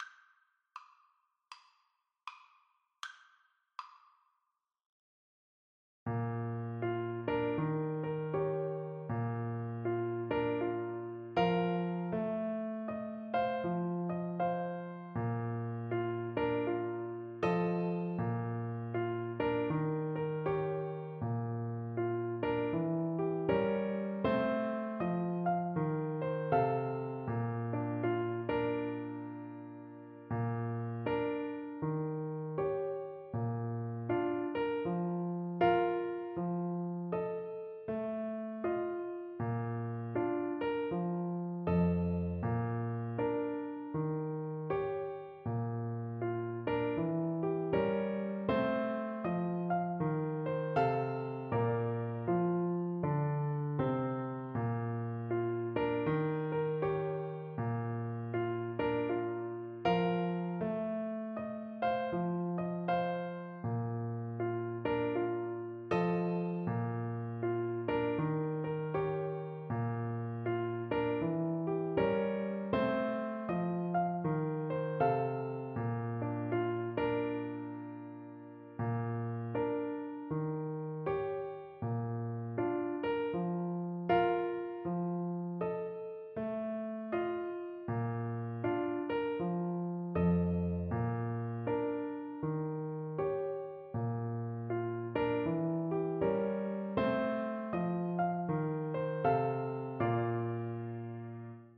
Play (or use space bar on your keyboard) Pause Music Playalong - Piano Accompaniment Playalong Band Accompaniment not yet available transpose reset tempo print settings full screen
Clarinet
Bb major (Sounding Pitch) C major (Clarinet in Bb) (View more Bb major Music for Clarinet )
Swing = 132
4/4 (View more 4/4 Music)